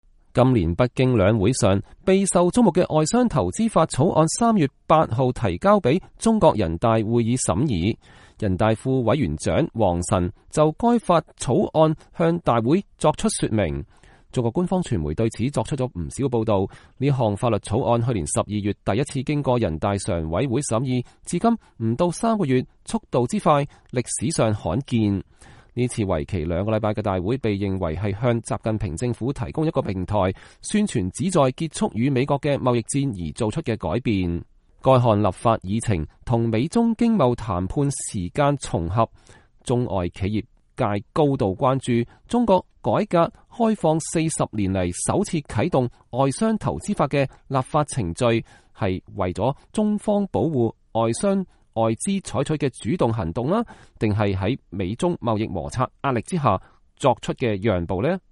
美國之音記者就上述問題隨機採訪了部分人大代表和政協委員，受訪的代表委員對推出此法的舉措多給予積極評價，但有些受訪者則對結構性改革問題閃爍其辭，迴避推託。
（根據現場採訪視頻整理，受訪者觀點不代表美國之音）